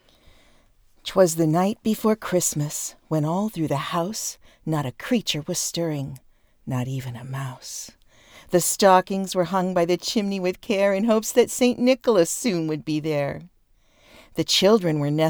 And I set up a “recording booth” in my walk in closet. I have covered the walls with heavy blankets and the noise floor always passes.
I think you’re good to go just like that. Voice quality is good and the test adjusts to perfect ACX standards with no other work.